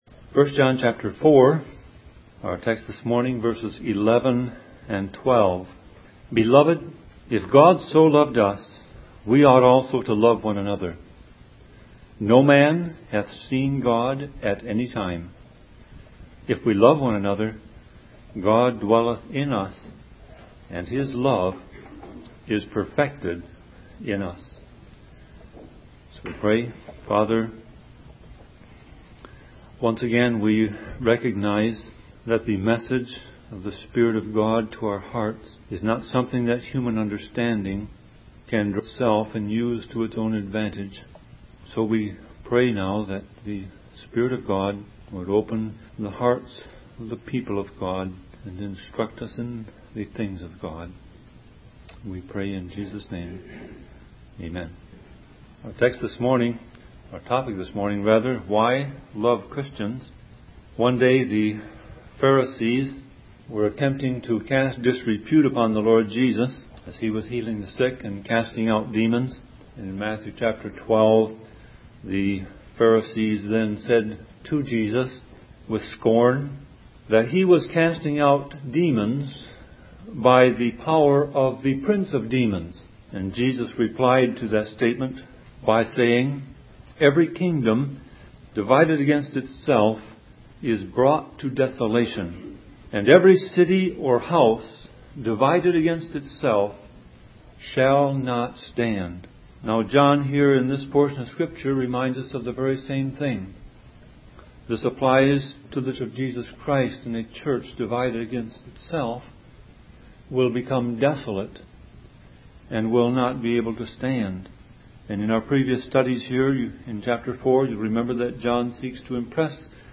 Sermon Audio Passage: 1 John 4:11-12 Service Type